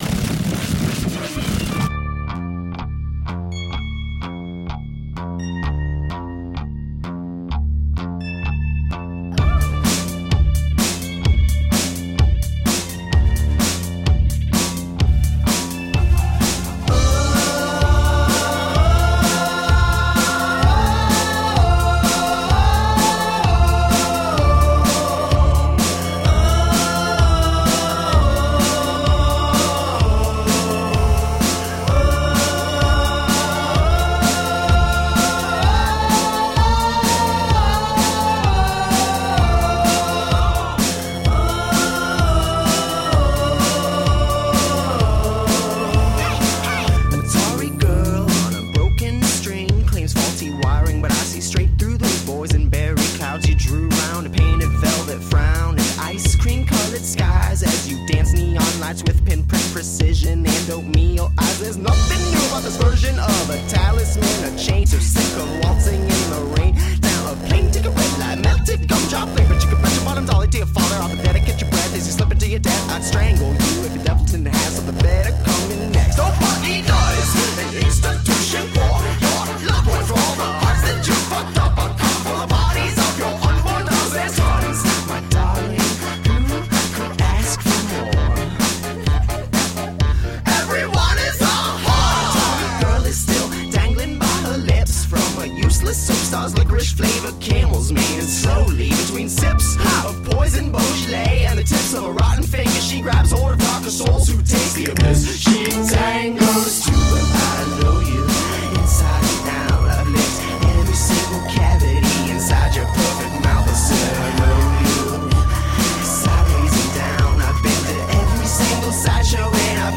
Sounds like synth pop walking down a dark alley.
Tagged as: Alt Rock, Folk-Rock, Prog Rock